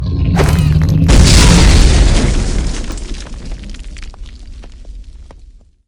grenade2.wav